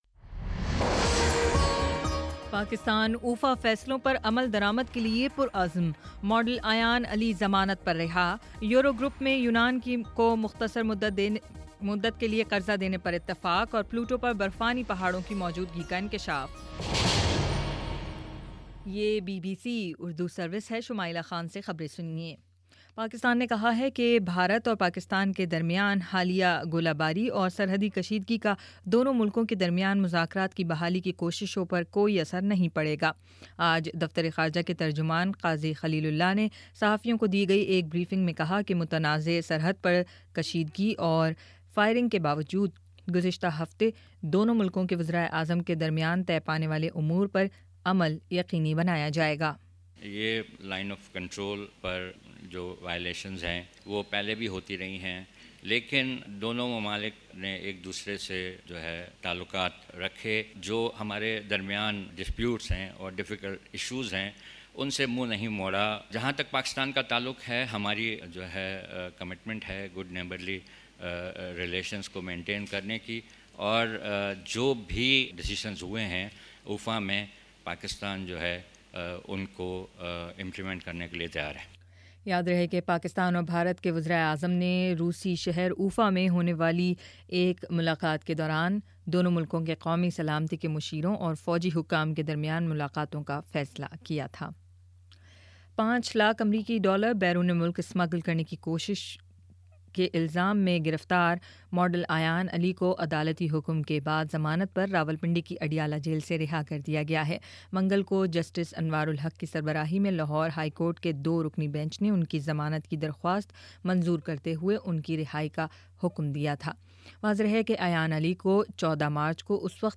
جولائی 16: شام سات بجے کا نیوز بُلیٹن